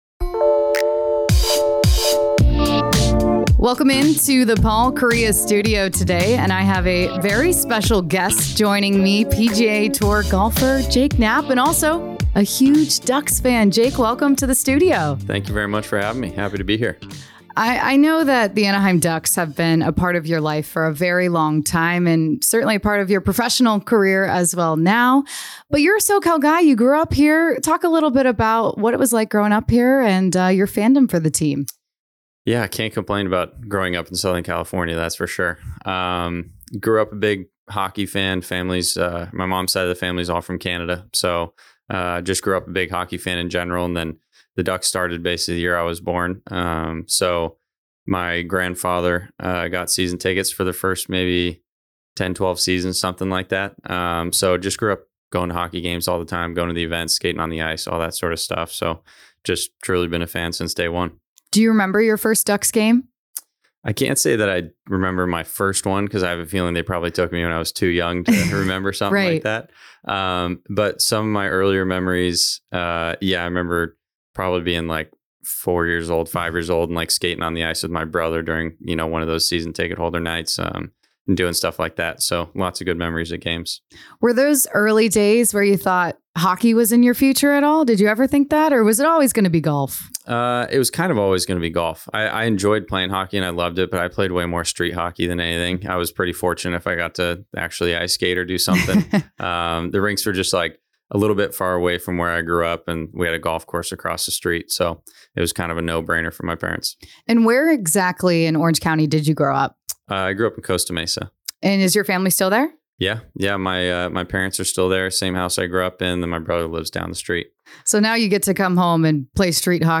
in the Paul Kariya studio for a special edition of Light the Lamp! Knapp shares the story behind his partnership with the Anaheim Ducks, repping the team at tour events and how his